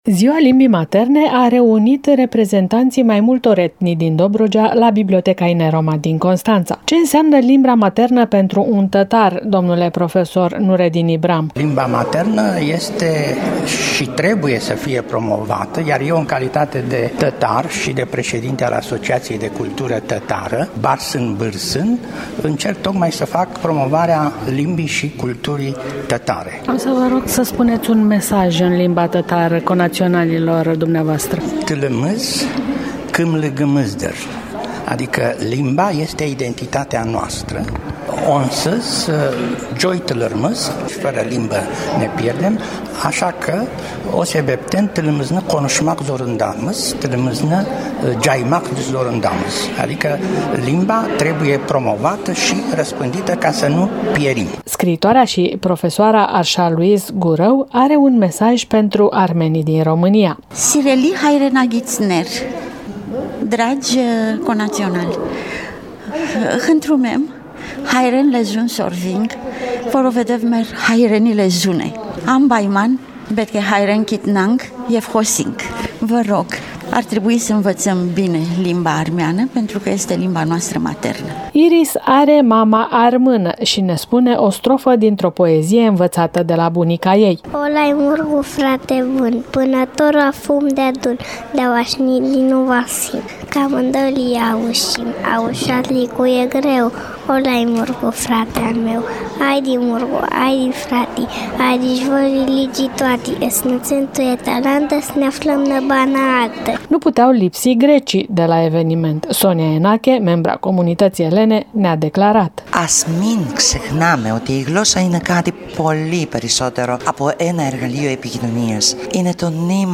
AUDIO | „Ziua Internațională a Limbilor Materne” a fost marcată la Biblioteca Județeană Constanța
Invitații au citit texte în limba maternă și în română, pentru a promova mozaicul etnic dobrogean prin intermediul valorilor culturale.